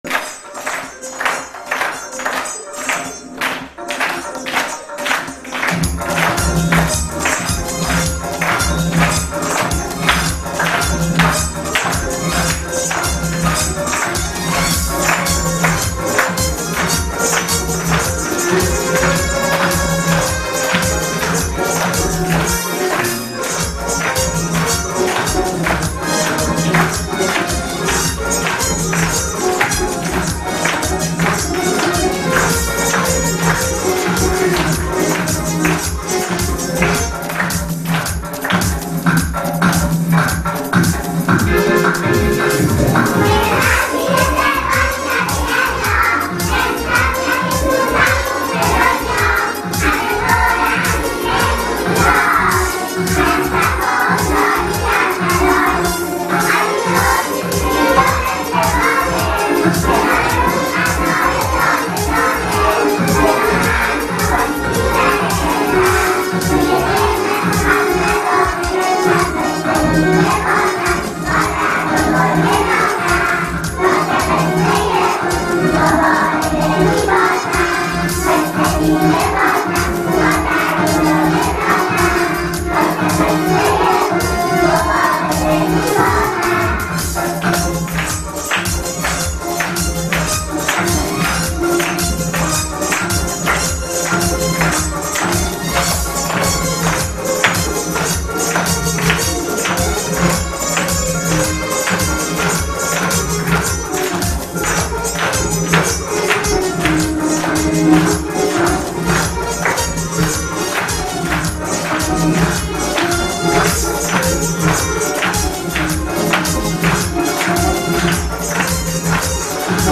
ترانه کودکانه